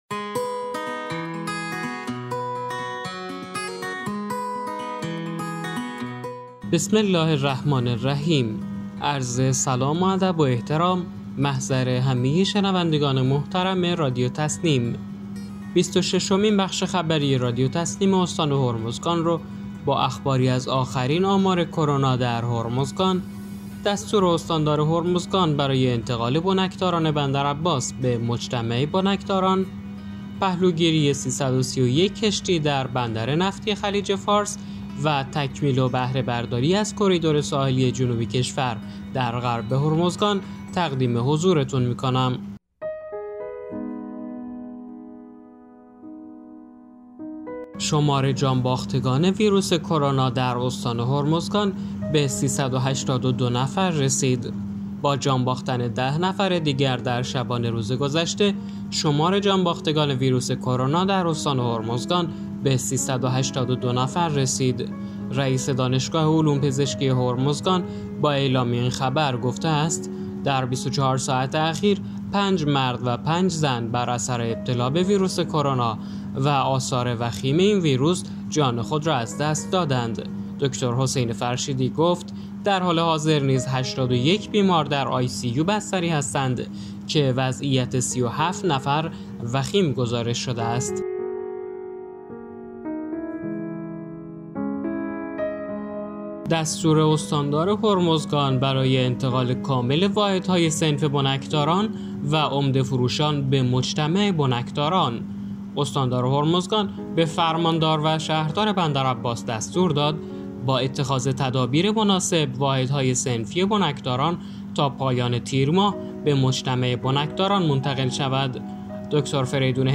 به گزارش خبرگزاری تسنیم از بندرعباس، بیست و ششمین بخش خبری رادیو تسنیم استان هرمزگان با اخباری از آخرین آمار کرونا در هرمزگان، دستور استاندار هرمزگان برای انتقال بنکداران بندرعباس به مجتمع بنکداران، پهلوگیری 331 کشتی در بندر نفتی خلیج فارس و تکمیل و بهره‌برداری از کریدور ساحلی جنوب کشور در غرب هرمزگان تا پایان سال جاری منتشر شد.